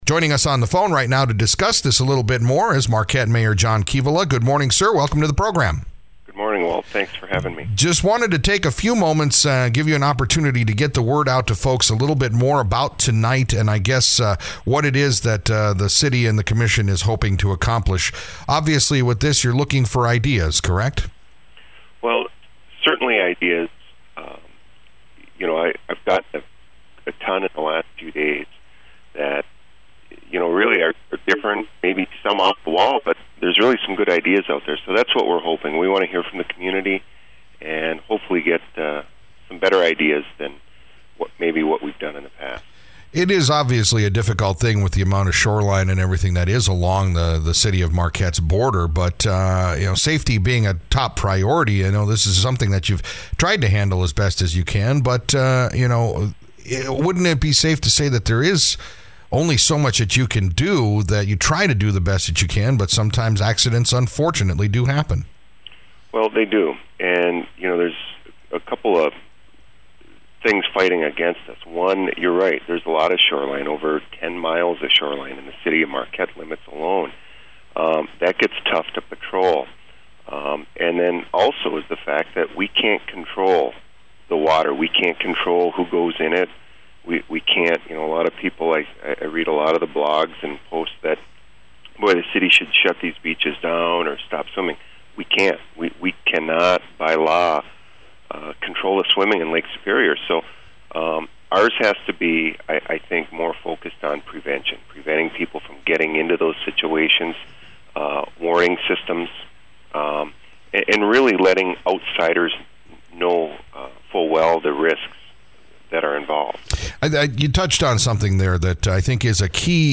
Marquette Mayor John Kivela called in this morning as a reminder for our listeners about the public forum coming up tonight at Marquette City Hall in Commission Chambers. The forum will be discussing beach front safety on the Lake Superior shorelines under the jurisdiction of the City of Marquette.